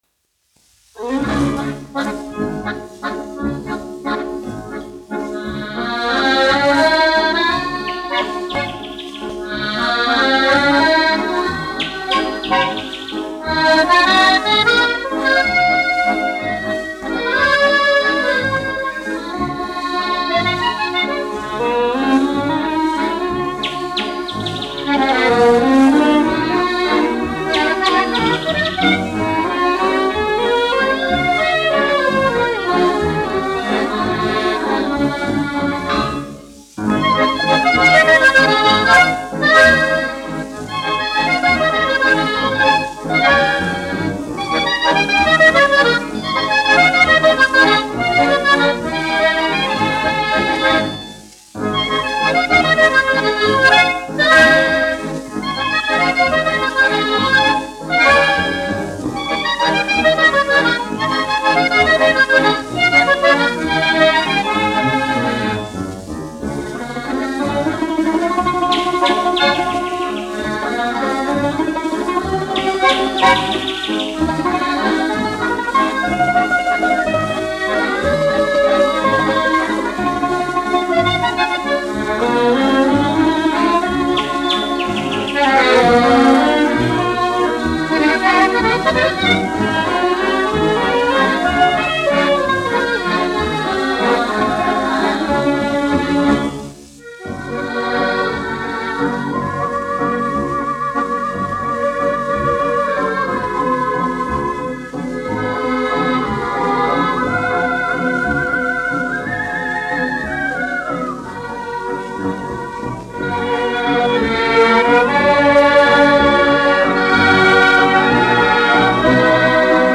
1 skpl. : analogs, 78 apgr/min, mono ; 25 cm
Populārā instrumentālā mūzika
Skaņuplate
Latvijas vēsturiskie šellaka skaņuplašu ieraksti (Kolekcija)